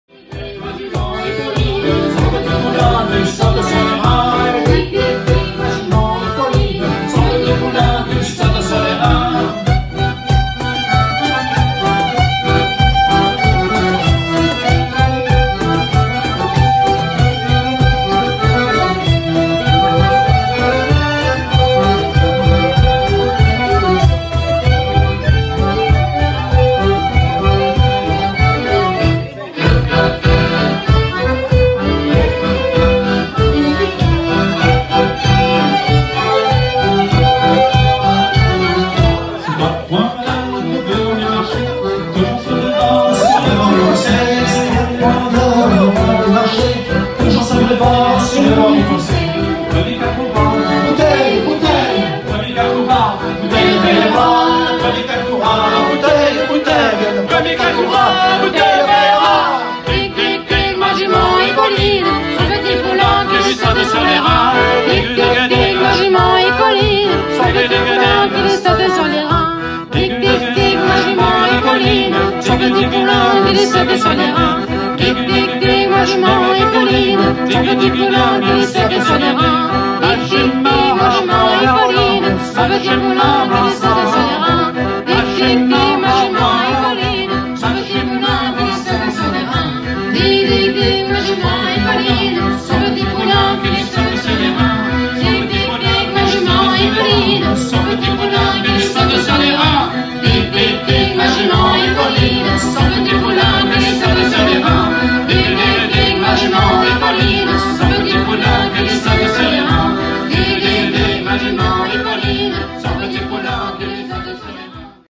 Andro ou En-Dro